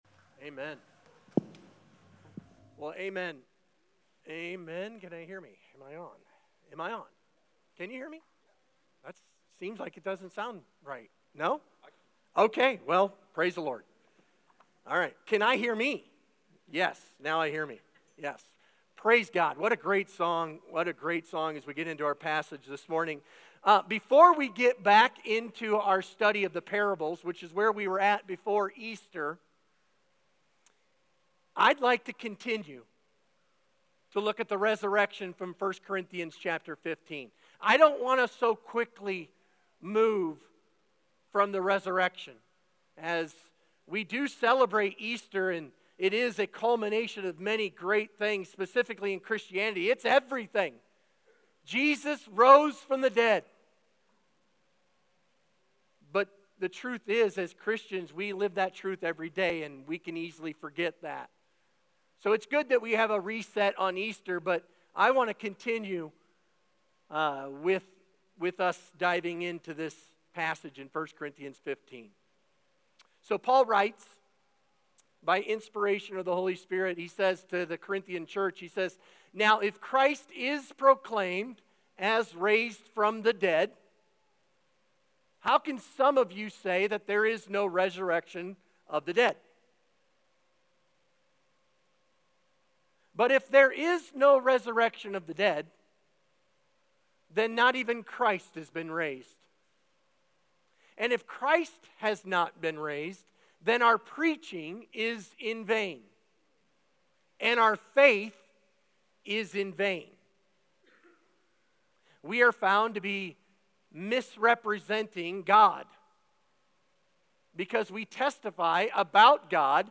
Sermon Discussion Read aloud: 1 Corinthians 15:12–19 Context: Paul is confronting a dangerous idea: what if there is no resurrection?